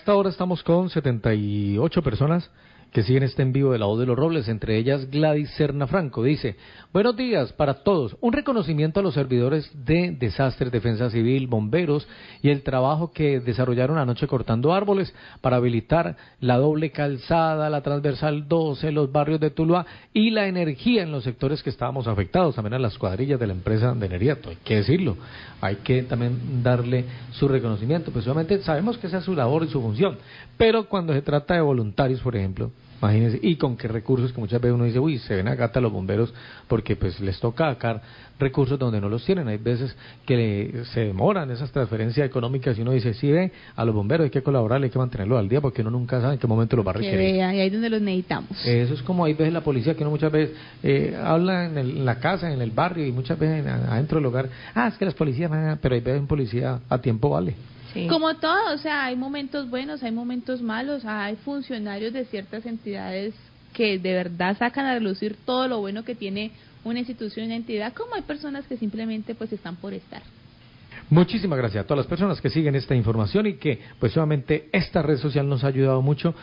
Oyente reconoce la labor las cuadrillas de la empresa de energía en la atención por el aguacero en Tuluá, los Robles, 646am